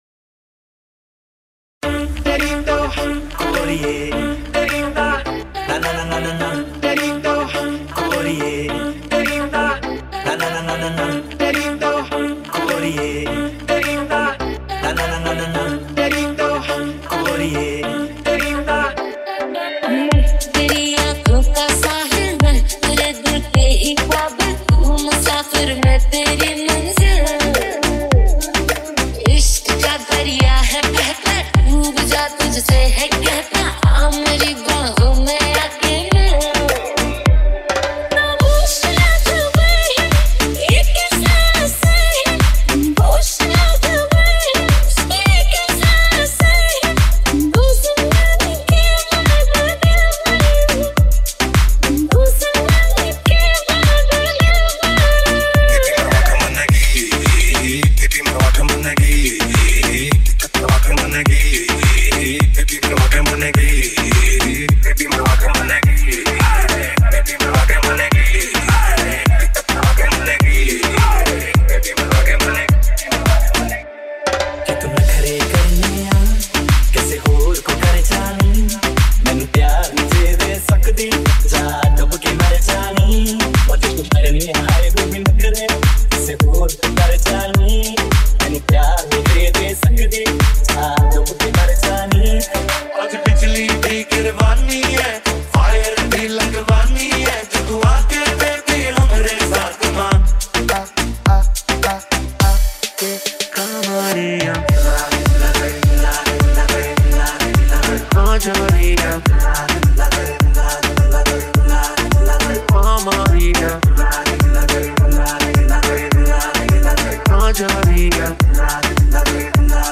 Hindi Mashup Remix